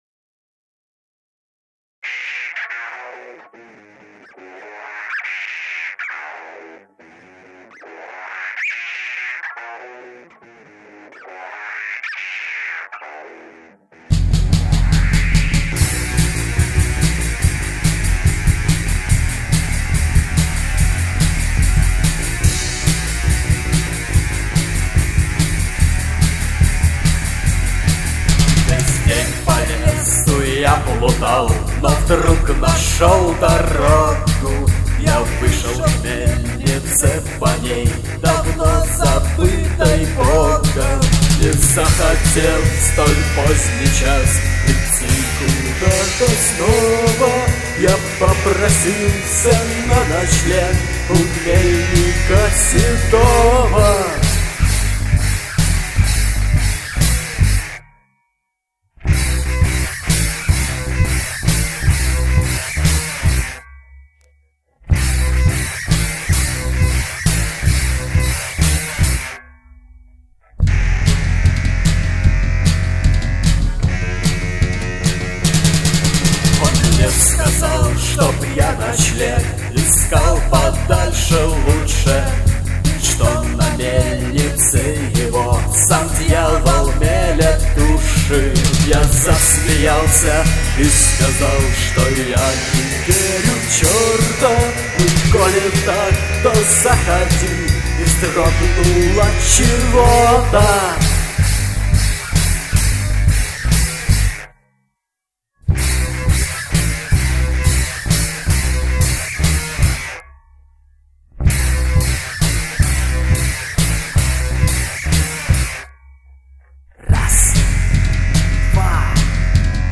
Гитары, бас, вокал
Барабаны